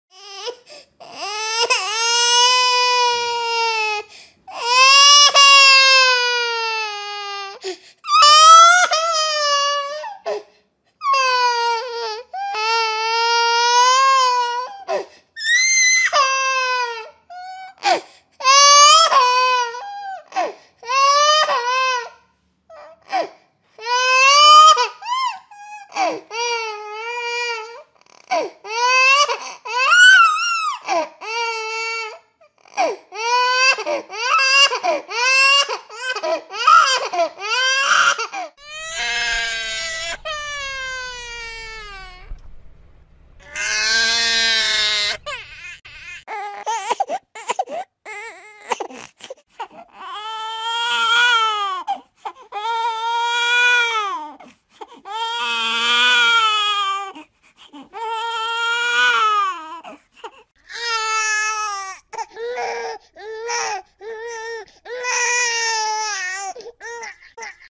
دانلود آهنگ گریه بچه 2 از افکت صوتی انسان و موجودات زنده
جلوه های صوتی
دانلود صدای گریه بچه 2 از ساعد نیوز با لینک مستقیم و کیفیت بالا